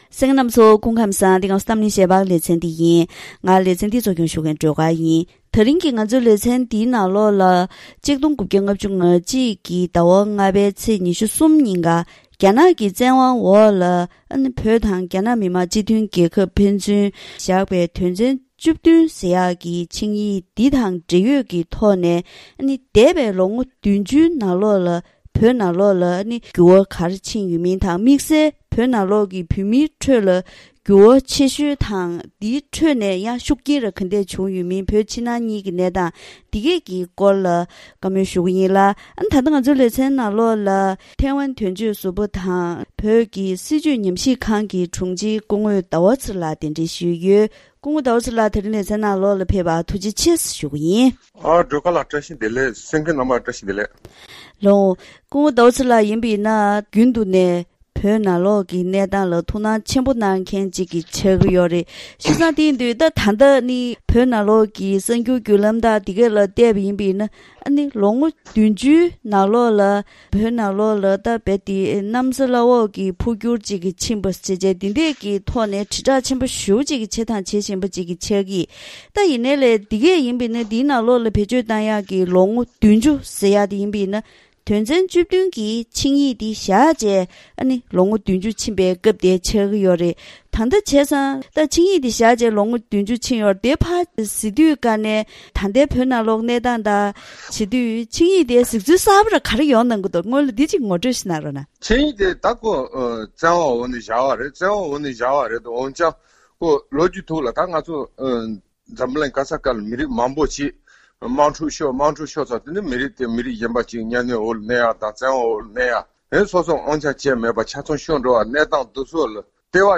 འདས་པའི་དུས་ཚོད་འདིའི་རིང་གི་བོད་དང་བོད་མིའི་གནས་སྟངས་སོགས་གནང་དོན་མང་པོའི་ཐོག་གླེང་མོལ་ཞུས་པ་ཞིག་གསན་རོགས་གནང་།།